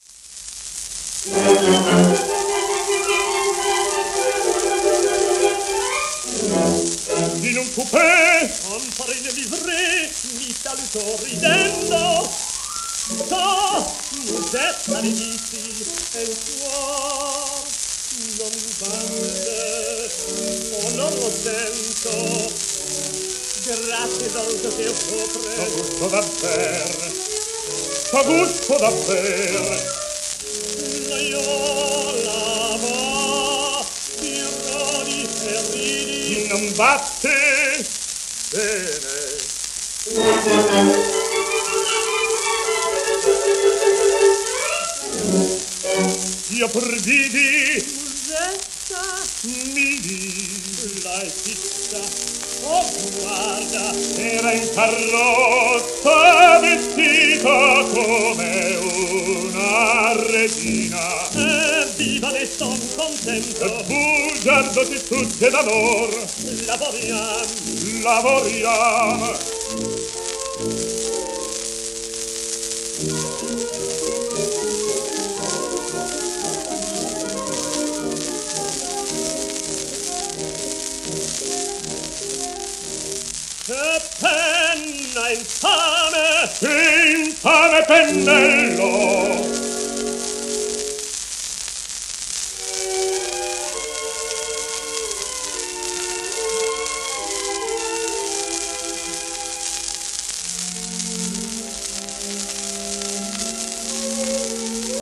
、オーケストラ